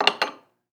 household
China Cup Set Down 2